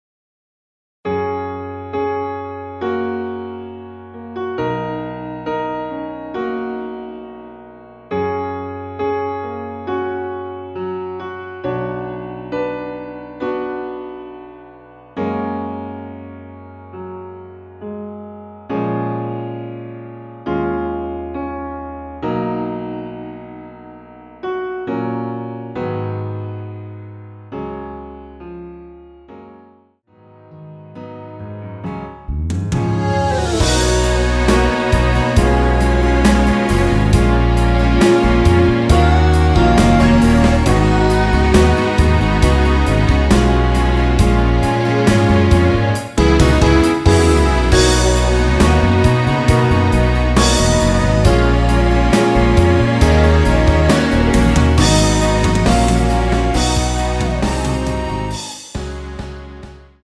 F#
음질 괜찮아요.
앞부분30초, 뒷부분30초씩 편집해서 올려 드리고 있습니다.
중간에 음이 끈어지고 다시 나오는 이유는